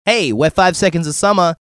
artist drop